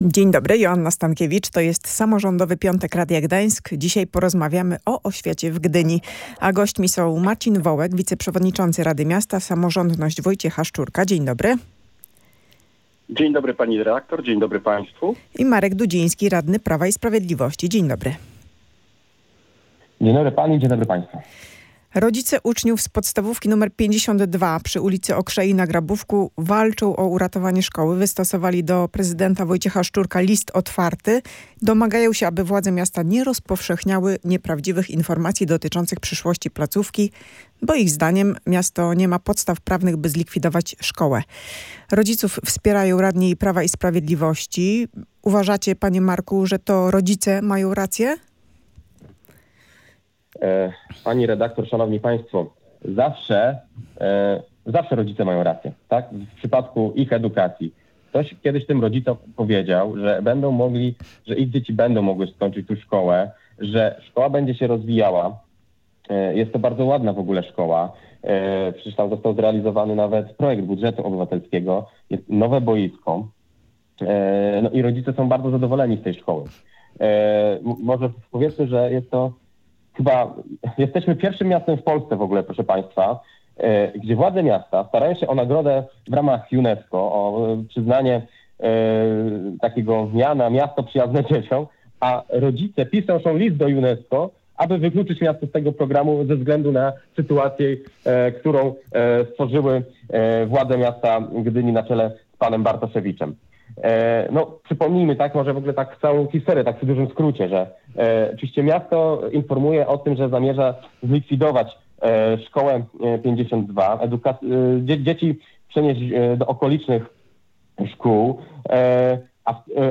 Jak od września i w kolejnych latach będzie wyglądała organizacja szkół w mieście? O tym rozmawiali radni – Marcin Wołek, wiceprzewodniczący rady miasta z Samorządności Wojciecha Szczurka oraz Marek Dudziński, radny Prawa i Sprawiedliwości.